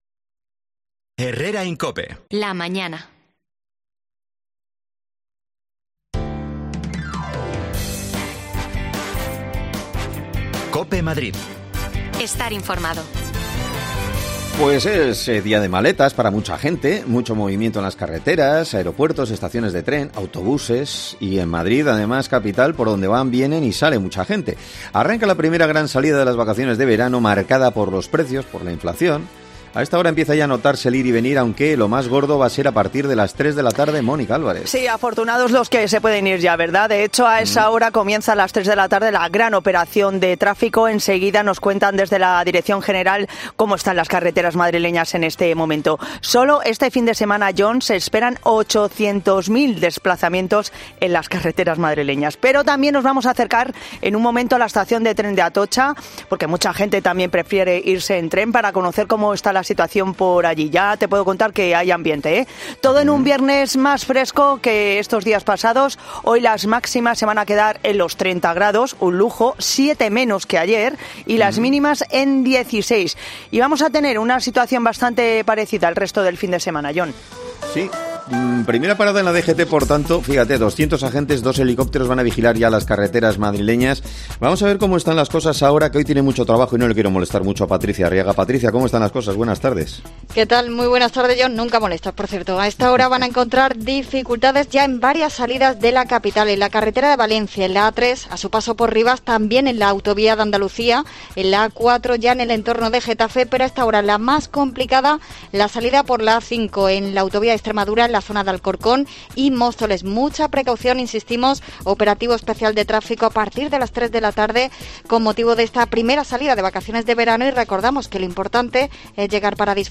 Nos acercamos a la estación de Atocha para conocer los planes de los viajeros en...
Las desconexiones locales de Madrid son espacios de 10 minutos de duración que se emiten en COPE , de lunes a viernes.